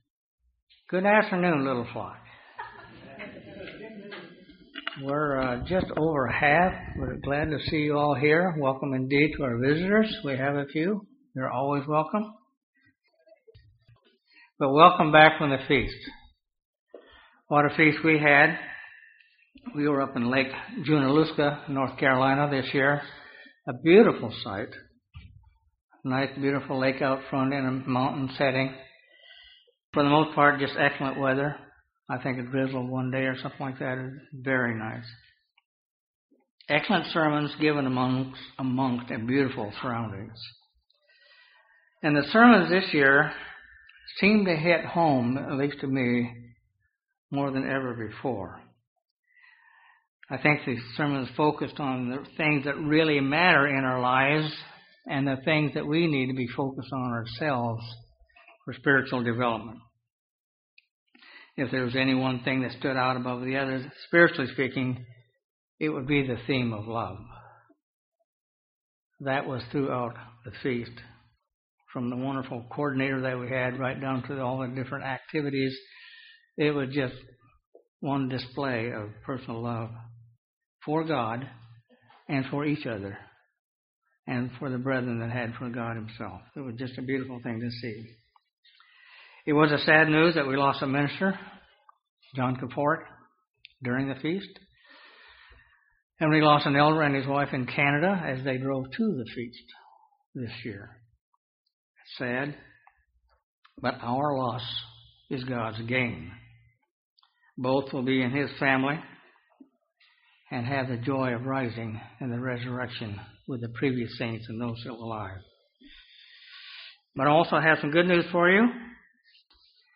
Given in Huntsville, AL